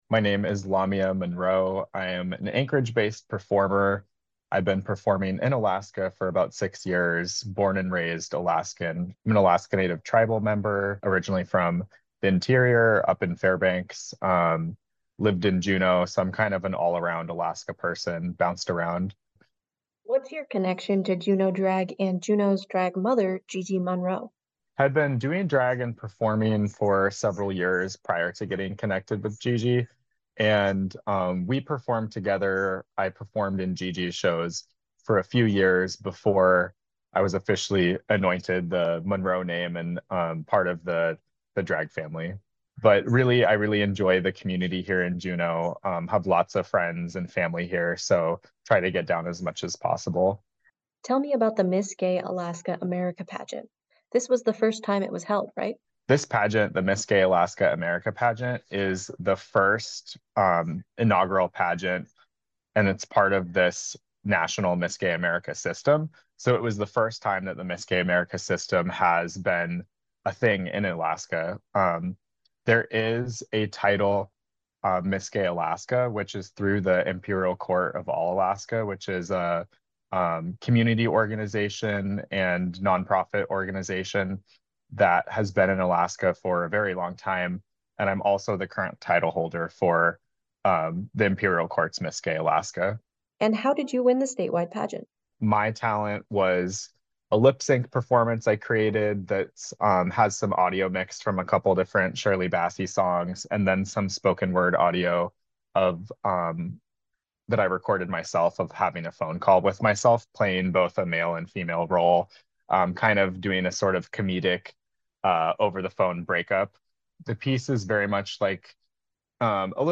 The interview was edited lightly to increase clarity and length.